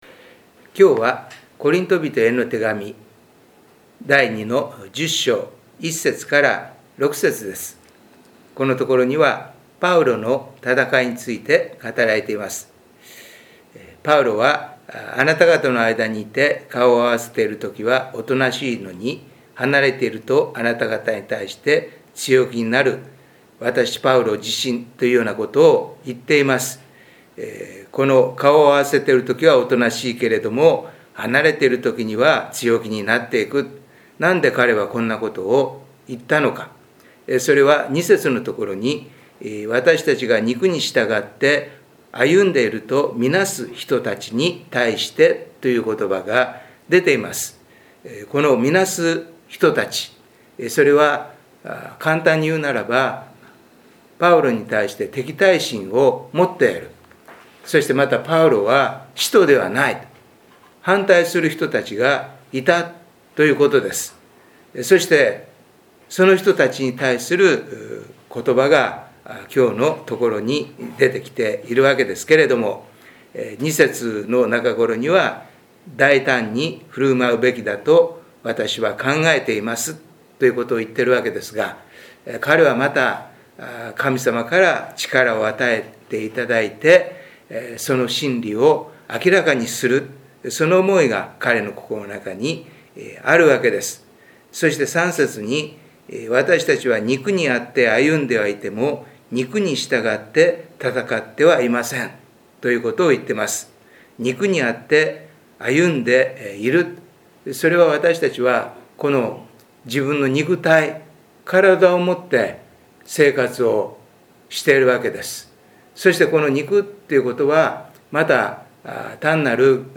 礼拝メッセージ「教会のかしら」│日本イエス・キリスト教団 柏 原 教 会